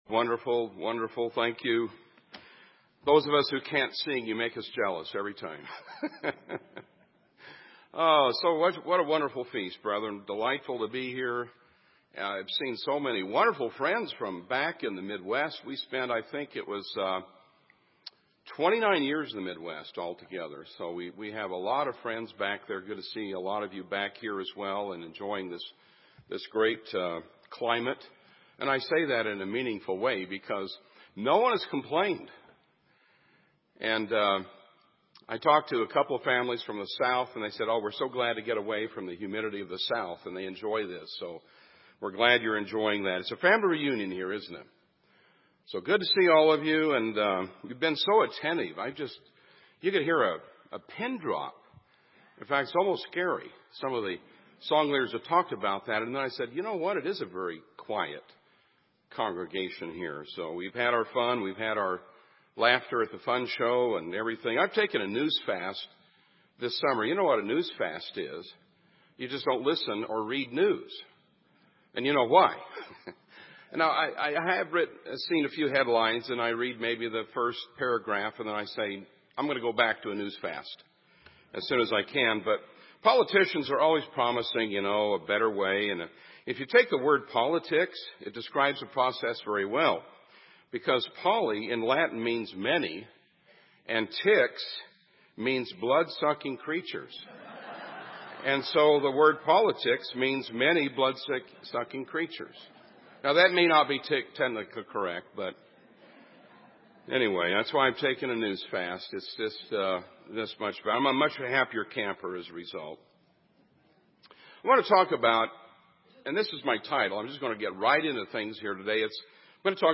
This sermon was given at the Bend, Oregon 2016 Feast site.